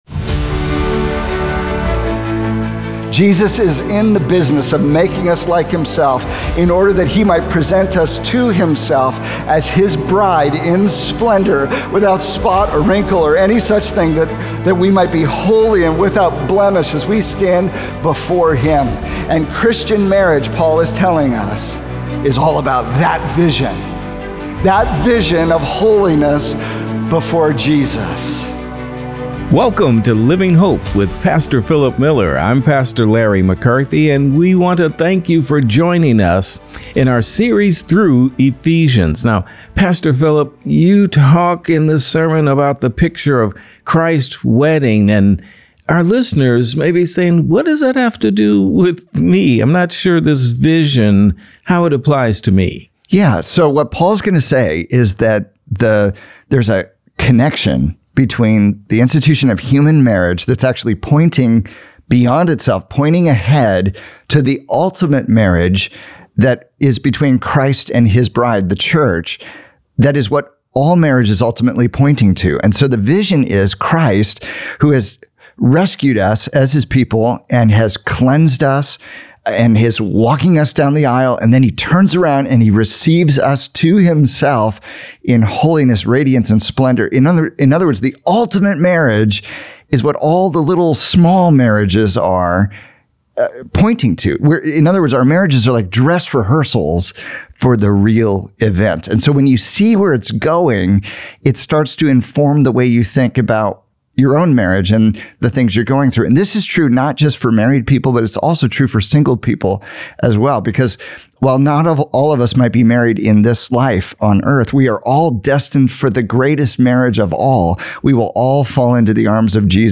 Ordinary Marriage and the Vision of Eternal Splendor | Radio Programs | Living Hope | Moody Church Media